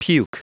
Prononciation du mot puke en anglais (fichier audio)
puke.wav